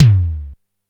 DX Tom 04.wav